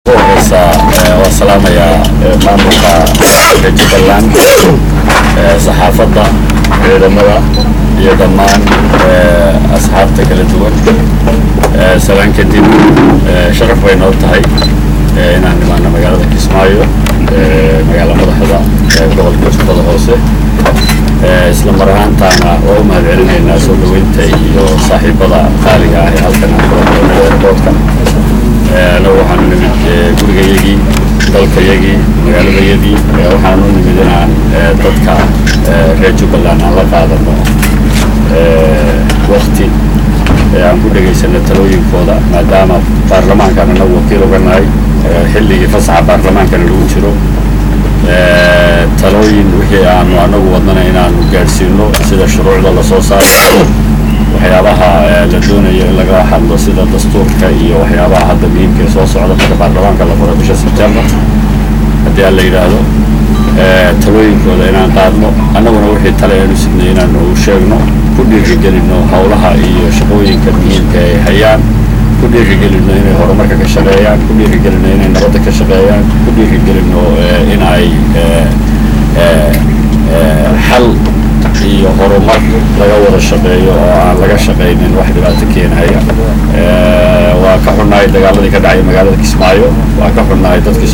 intaasi ka dib waxaa warbaahinta la hadlay xildhibaan cabdi rashiid xidig waxaana uu ka warbixyay safarkiisa uu ku yimid magaalad Kismaayo.
ugu danbayntii waxaa hadlay gudoomiyha arimha bulshda ee magaalada Mismaayo Cabdullahi Khaldaan waxaan uu ka hadalay imaansha wafdiga kismaayo iyo sidoo kale markab raashinka waday ee laga soo celiyay puntaland oo uu sheegay in uu haatan ku soo wajahanyahay magaald Kismaayo.